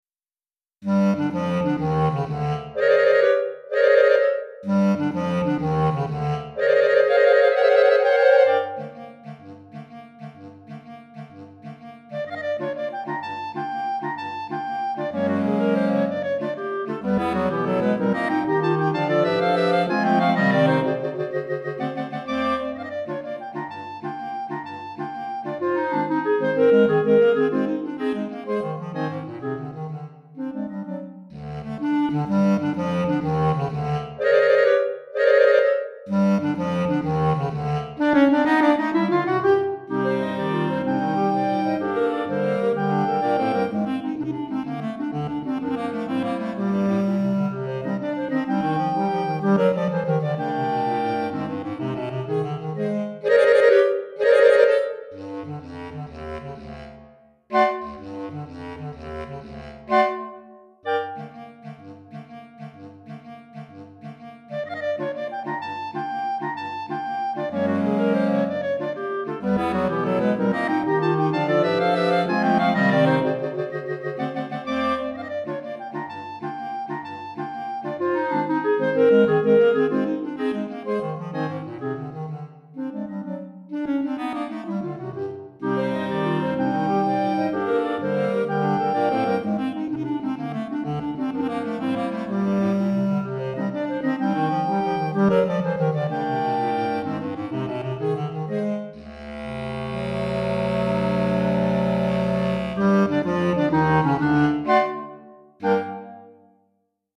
3 Clarinettes en Sib, Clarinette Alto Mib